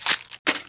TrashCan1.wav